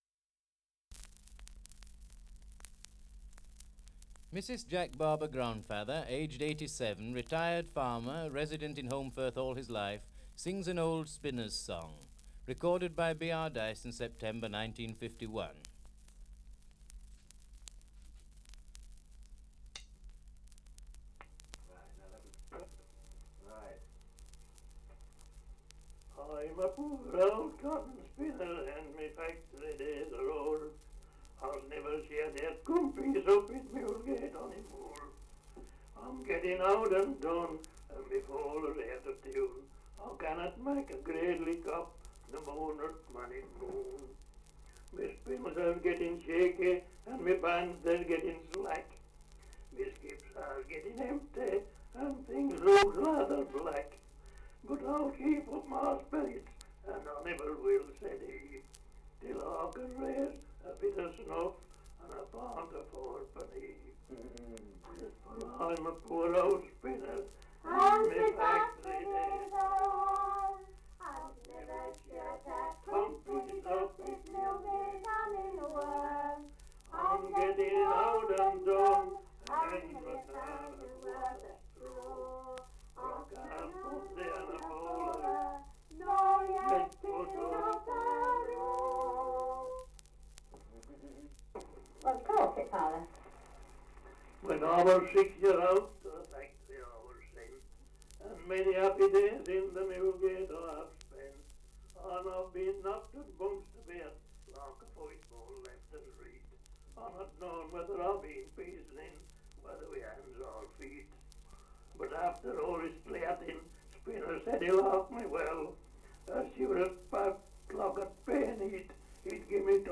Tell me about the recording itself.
Survey of English Dialects recording in Holmbridge, Yorkshire. Dialect recording in Holmfirth, Yorkshire 78 r.p.m., cellulose nitrate on aluminium